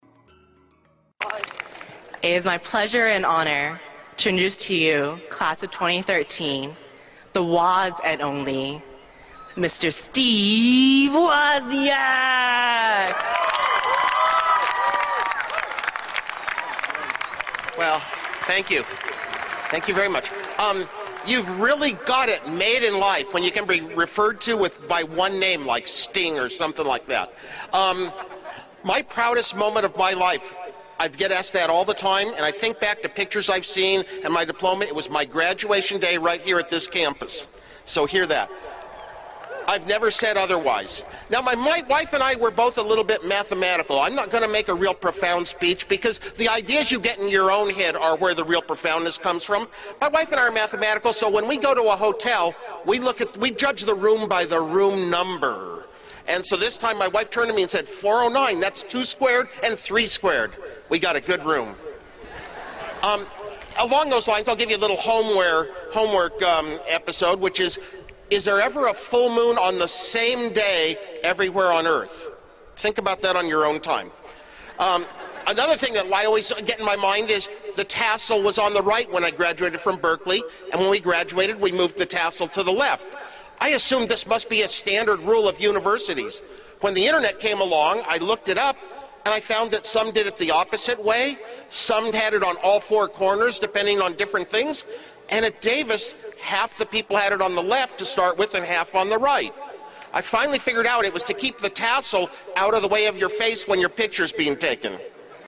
公众人物毕业演讲 第144期:史蒂夫·沃兹尼亚克于加州大学伯克利分校(1) 听力文件下载—在线英语听力室